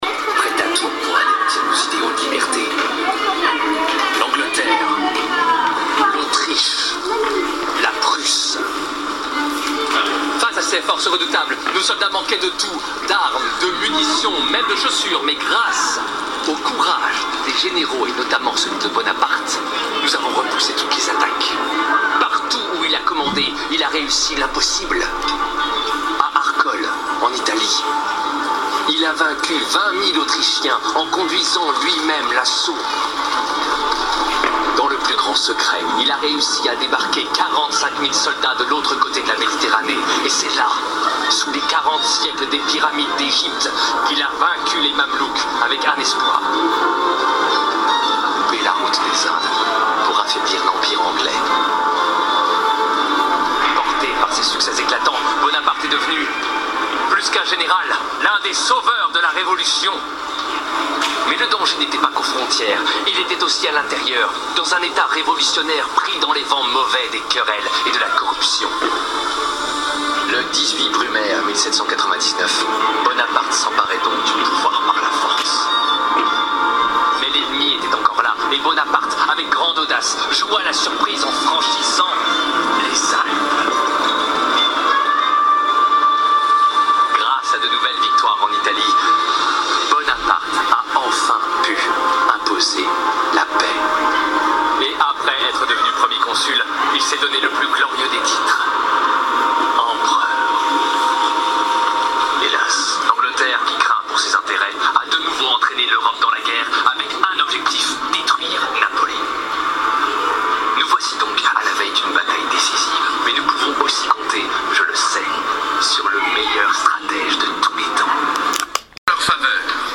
En regardant le spectacle au travers de mon casque, j'ai enregistré le son de ce que je voyais
(désolé pour la qualité du son et des bruits de fond)..